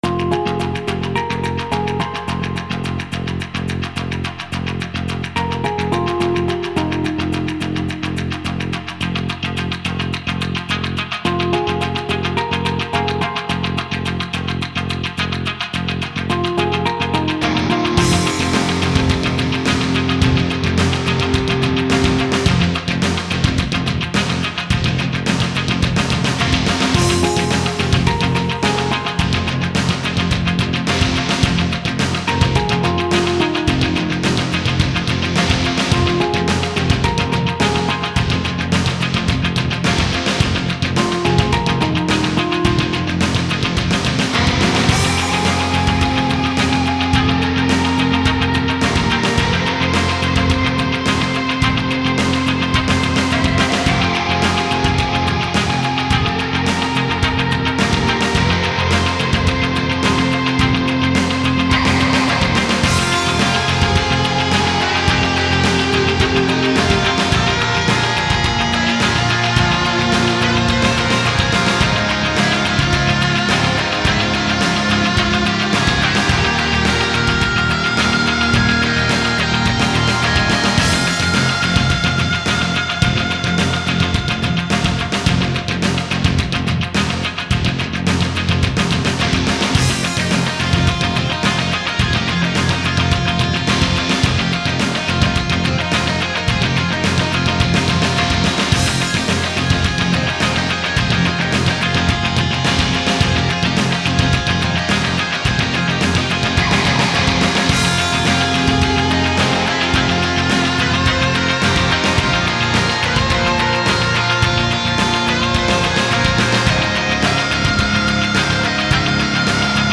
An attempt at rock music